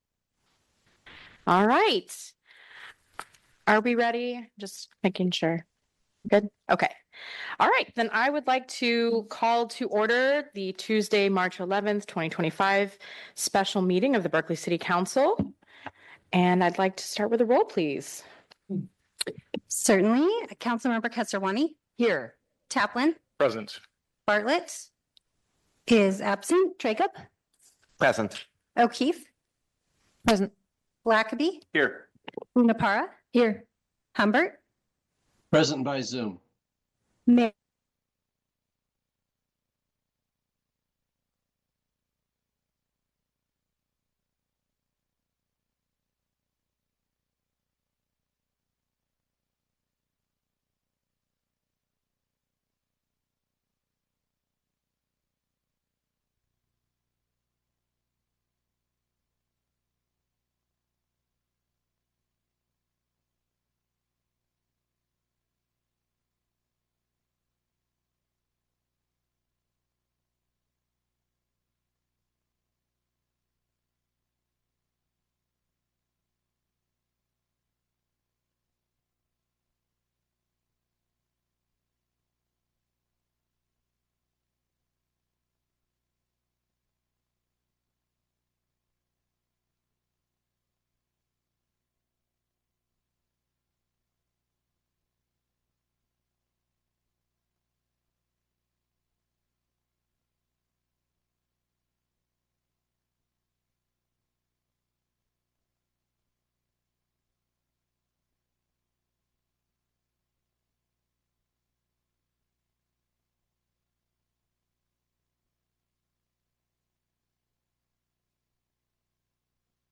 This meeting will be conducted in a hybrid model with both in-person attendance and virtual participation.